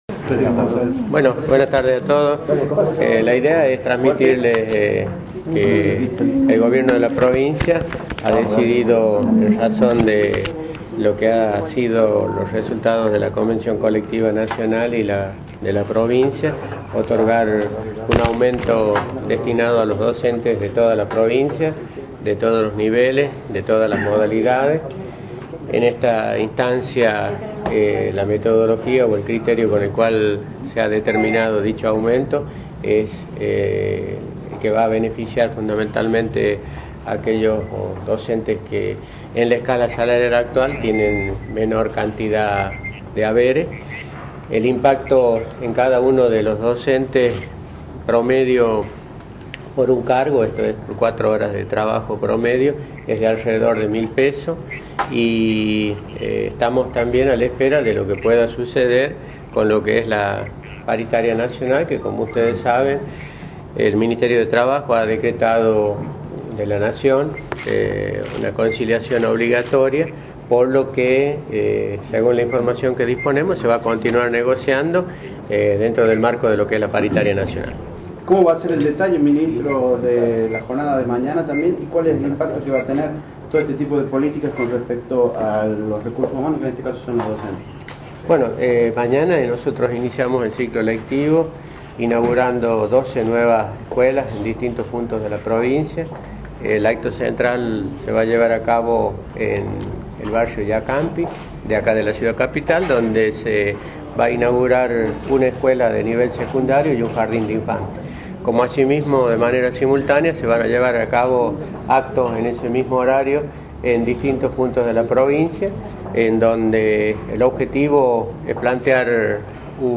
Conferencia de prensa de funcionarios provinciales
En rueda de prensa en la Residencia Oficial, sin la presencia del gobernador Luis Beder Herrera, los ministros Ricardo Guerra (Hacienda) Walter Flores (Educación), entre otros, anunciaron un aumento de 1.000 pesos para los maestros, mientras que este miércoles habrá una movilización dentro de un plan de lucha por 48 horas.